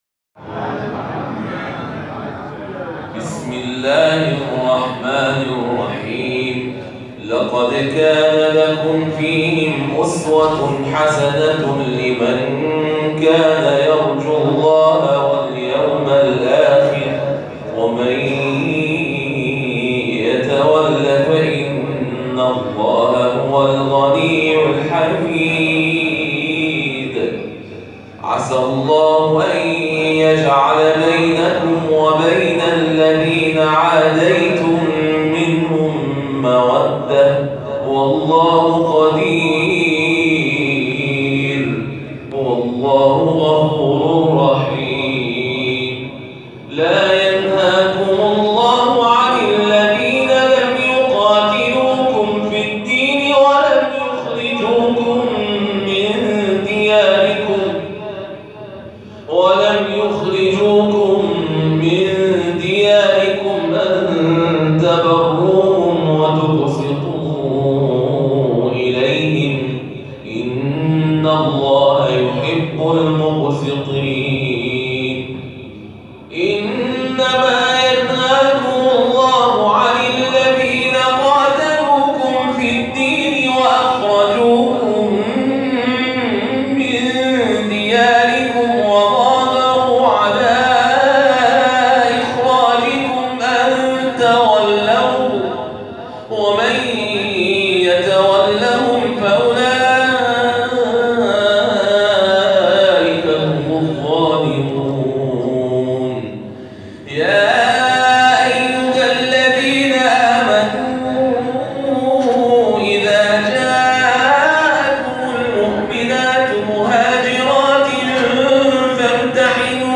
گروه مؤسسات قرآنی مردم‌نهاد: نهمین جلسه آموزشی حفظ، قرائت و مفاهیم قرآن کریم مؤسسه کریمه‌ آل‌رسول(سلام الله علیها) در قم برگزار شد.